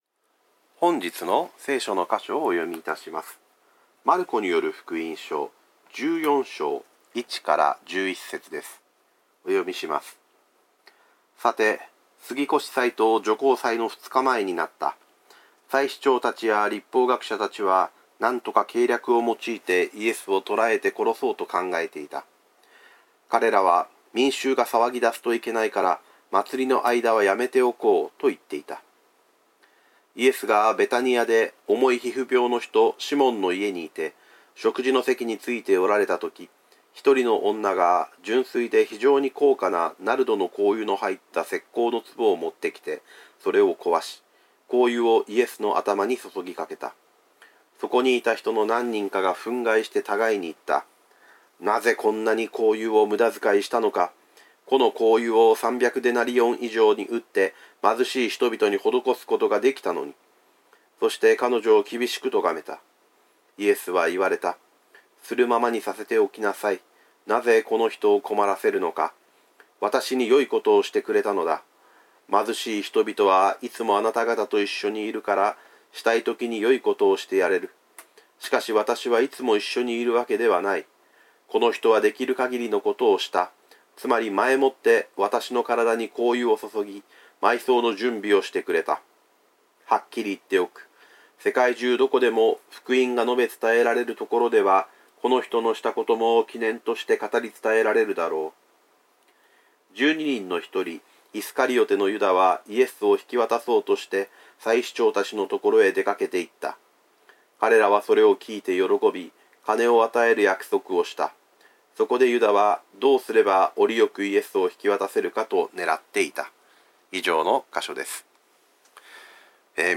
久留米キリスト教会の主日礼拝